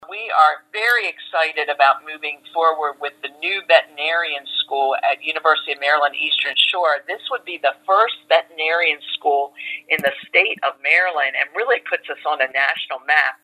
Senator Carozza tells the Talk of Delmarva what other investment will make a real difference locally…